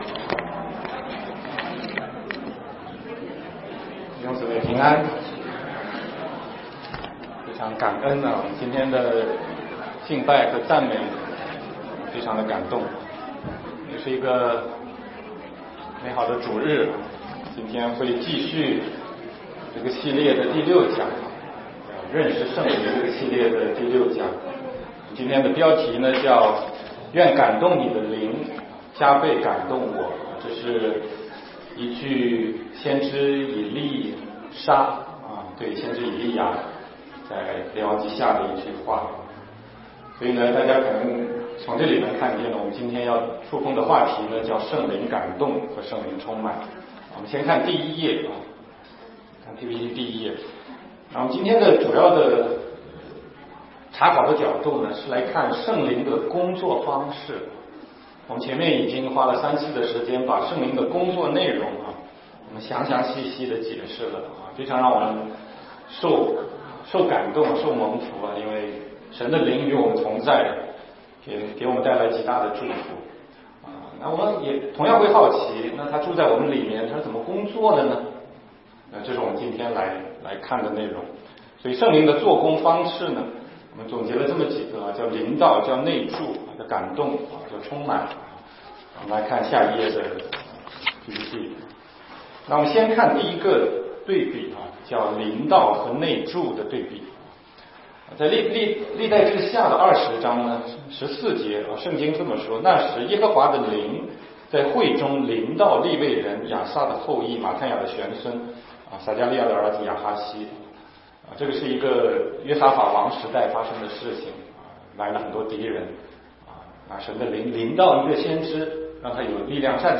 16街讲道录音 - 认识圣灵系列之六：愿感动你的灵加倍感动我